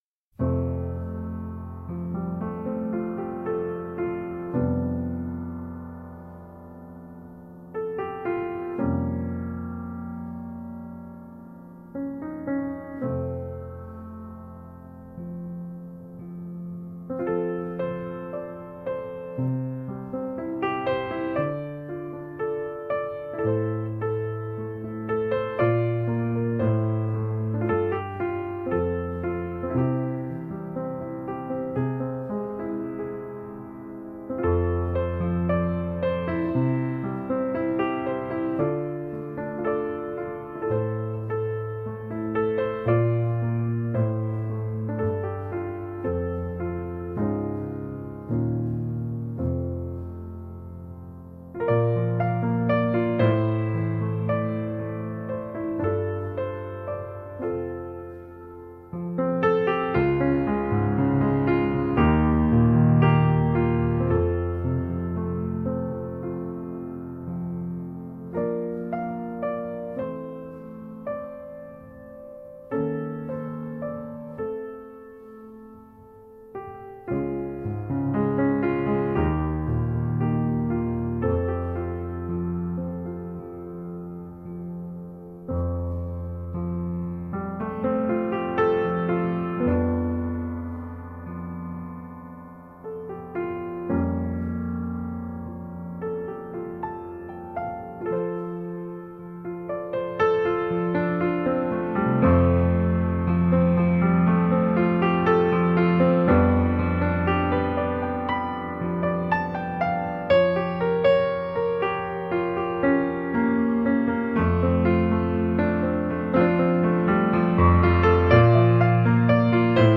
Tagged as: New Age, Classical, New Age Piano